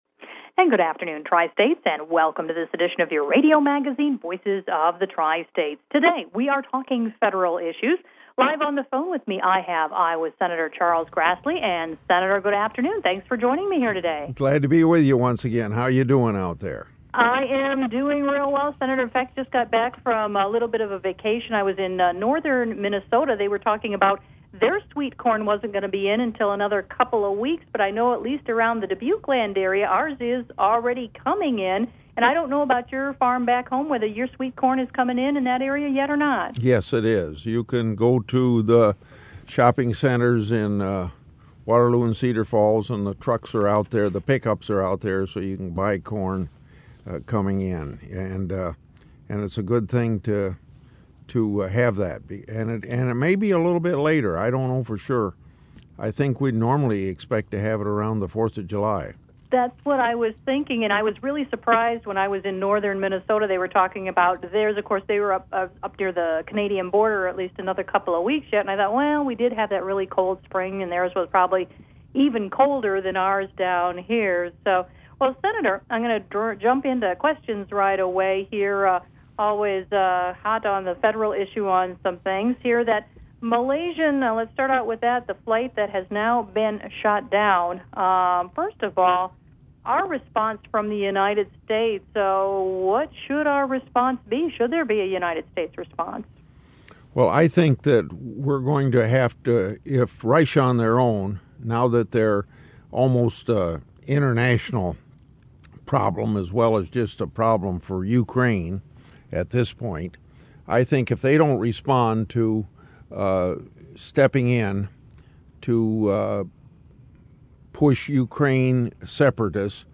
Grassley Live on KDTH